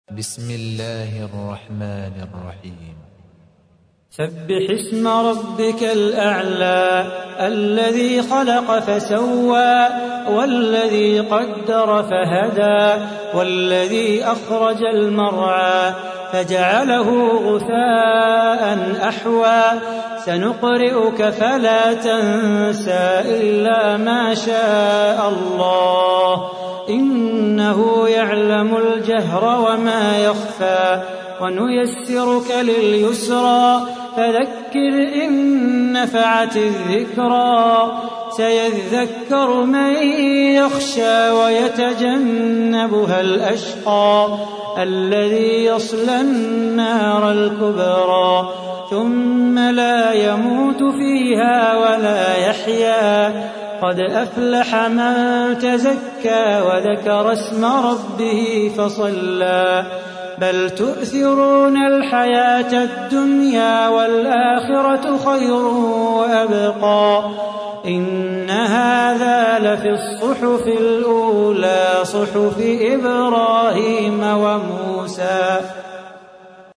تحميل : 87. سورة الأعلى / القارئ صلاح بو خاطر / القرآن الكريم / موقع يا حسين